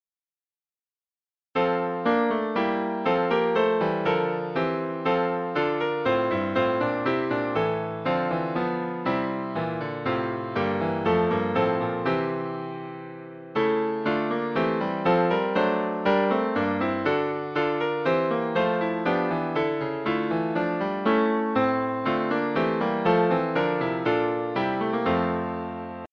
Music by: German melody;